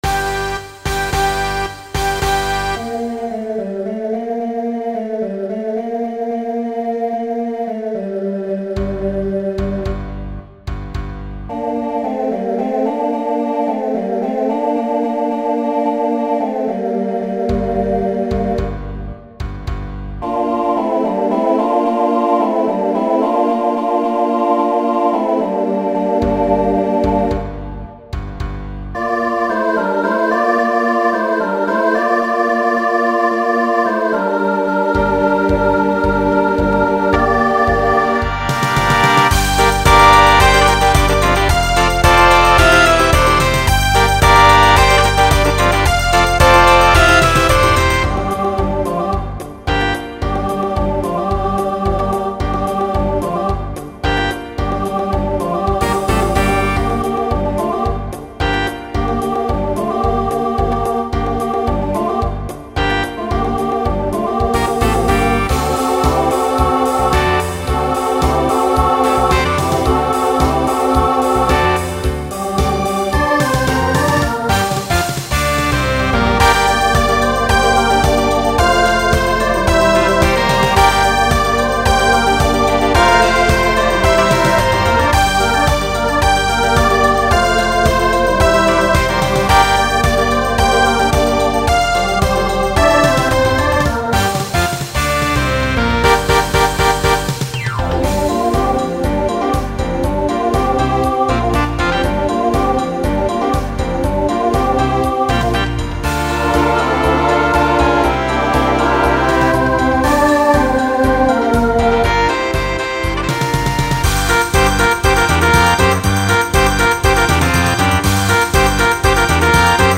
Genre Rock Instrumental combo
Voicing SATB